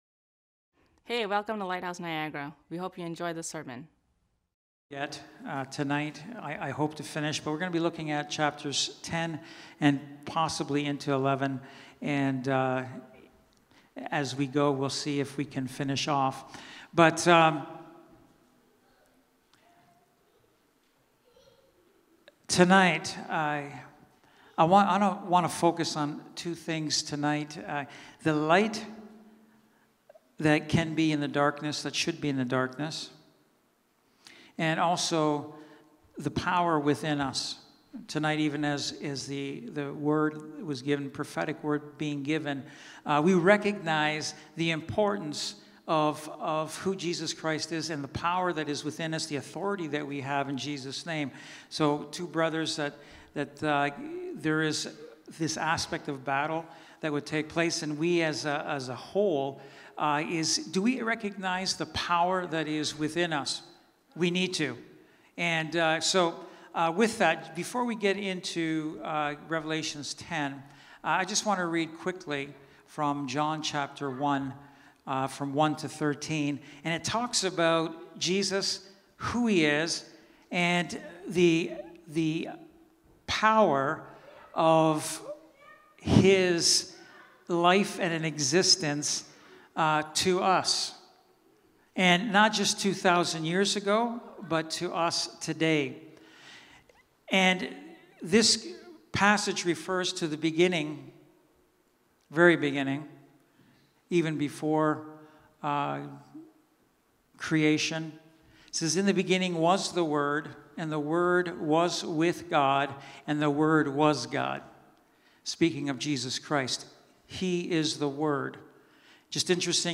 Wednesday Night Bible Study